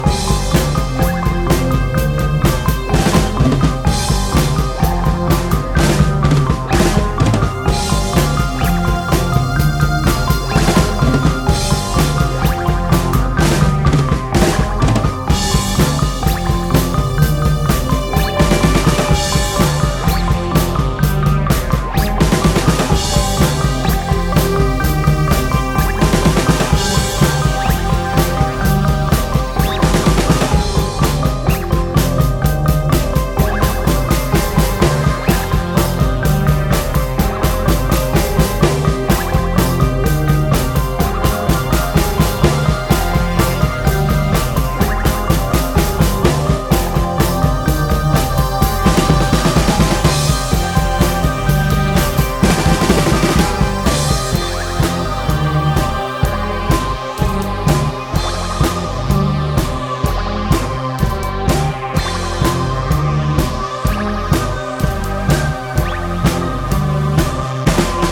¥1,380 (税込) ROCK / 80'S/NEW WAVE.